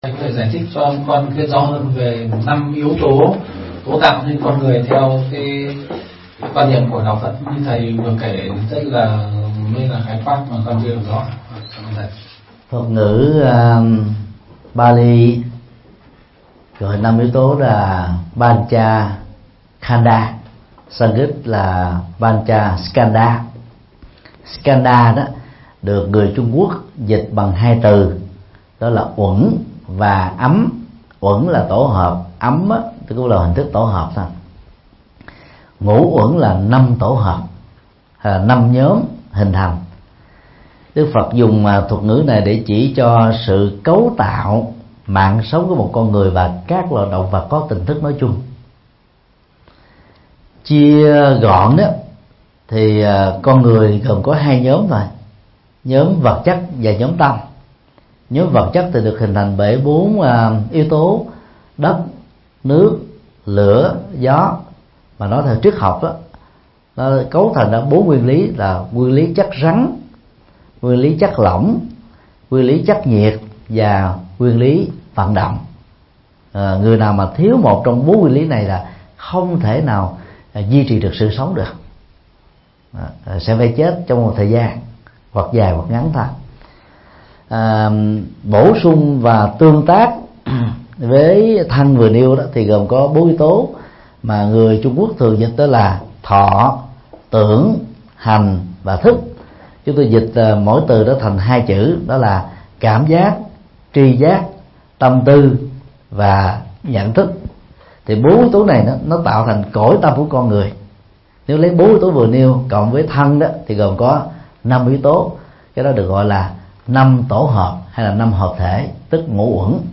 Vấn đáp: Ngũ uẩn, mục tiêu của cuộc đời – thầy Thích Nhật Từ
Mp3 Vấn đáp: Ngũ uẩn, mục tiêu của cuộc đời – Thầy Thích Nhật Từ Giảng tại Niệm Phật đường Giác Tuệ, Dresden, Đức, ngày 20 tháng 6 năm 2015